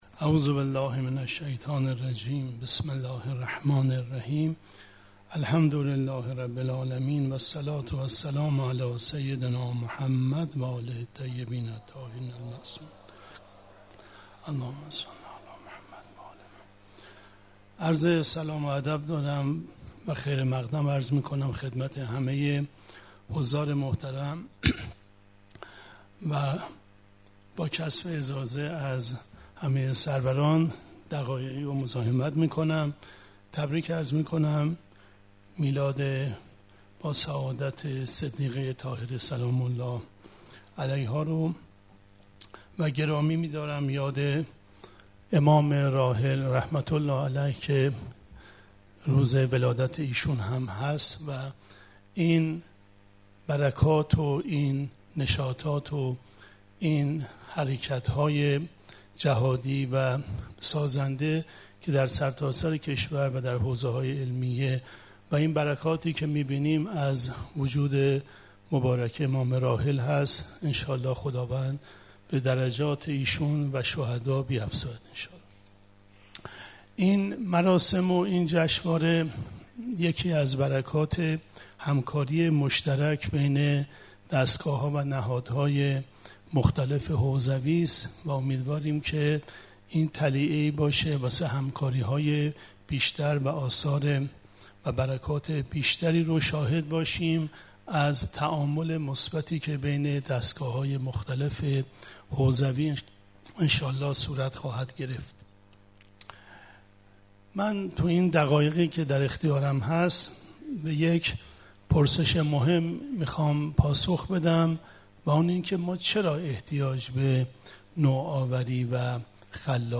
سخنرانی
در آئین اختتامیه جشنواره ملی نوآوری‌های تبلیغی «جنات»